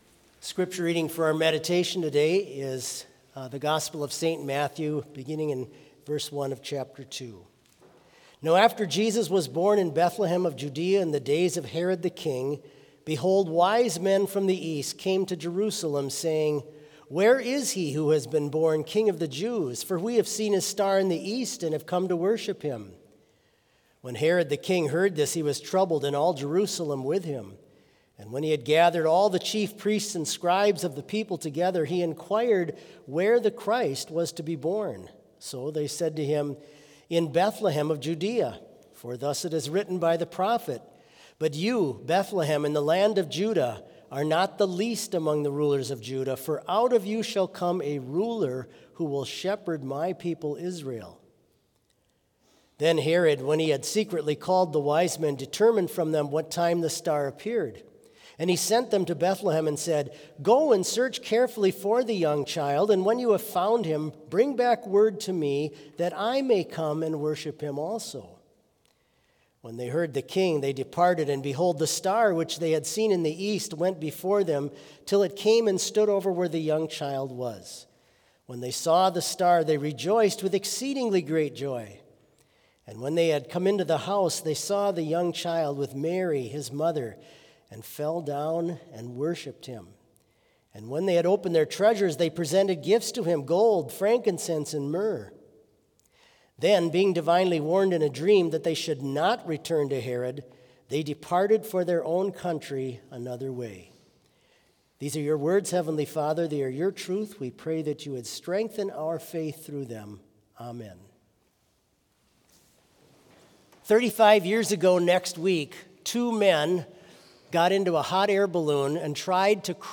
Complete service audio for Chapel - Friday, January 9, 2026
Hymn 148 - Praise God the Lord, Ye Sons of Men